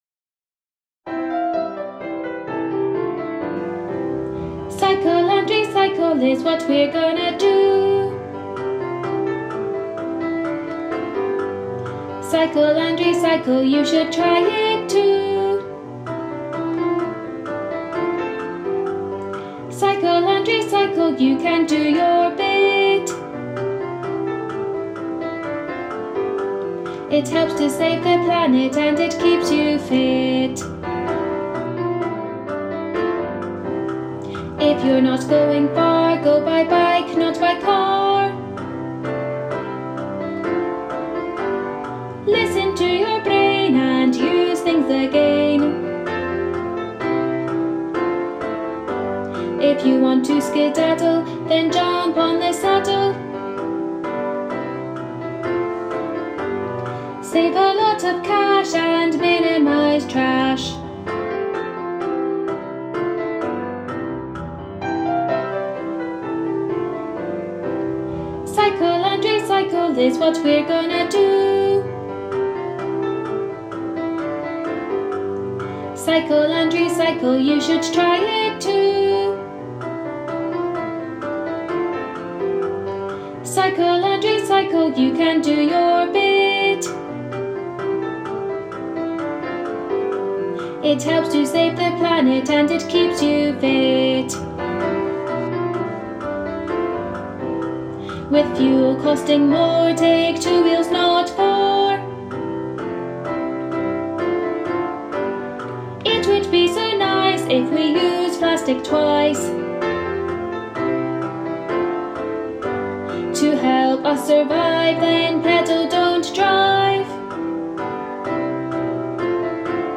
Teaching Track
Cycle-and-Recycle-Teaching-track.m4a